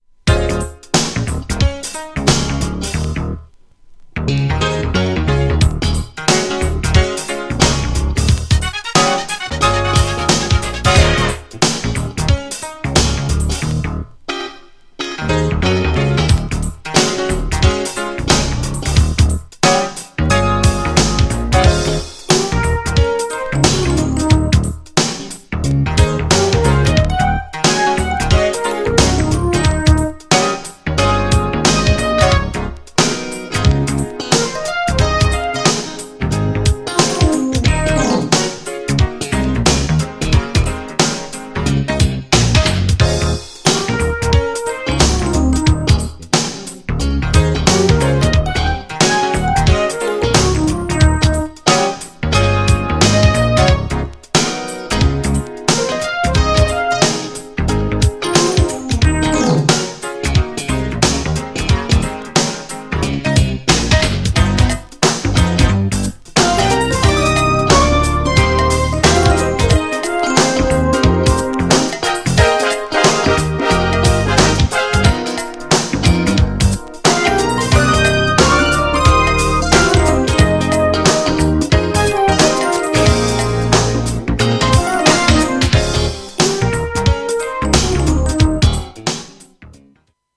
GENRE Dance Classic
BPM 96〜100BPM